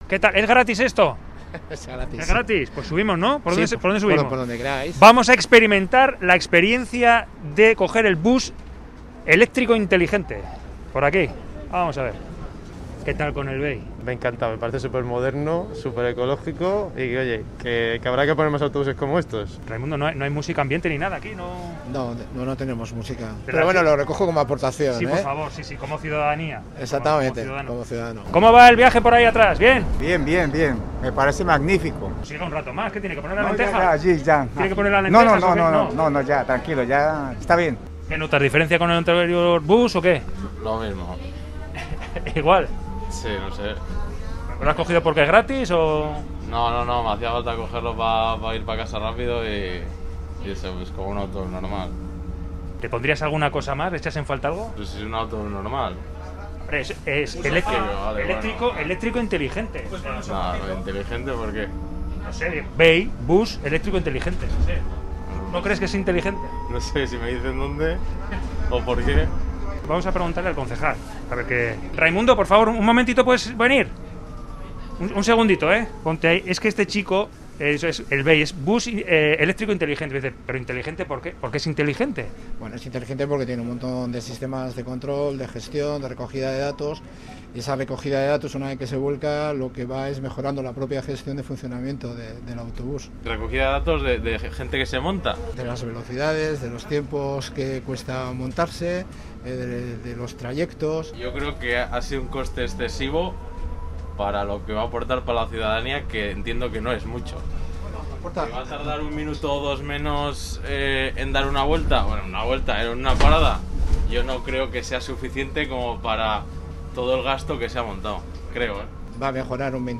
Audio: Entre la tripulación varios ciudadanos alaveses y un reportero de Radio Vitoria. Las sensaciones que transmite viajar en un Bus Eléctrico Inteligente y, si hay dudas, se le pregunta a la autoridad competente.